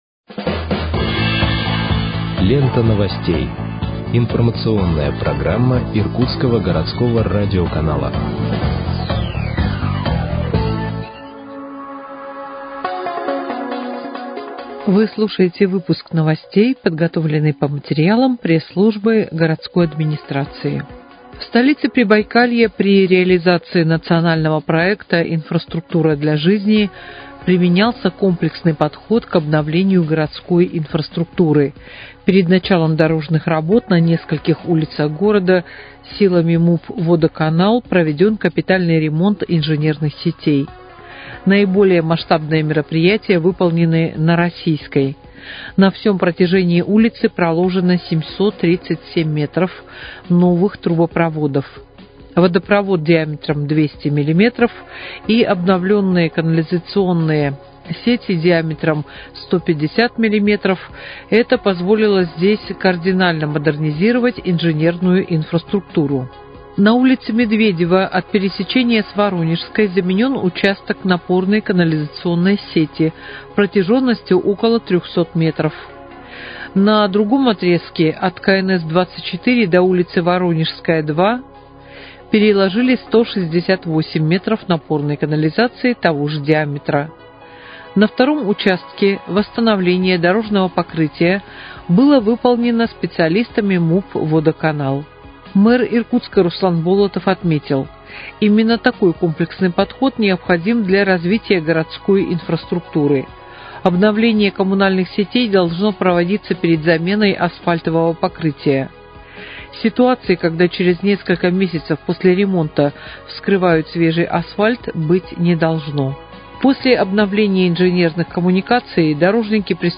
Выпуск новостей в подкастах газеты «Иркутск» от 26.11.2025 № 1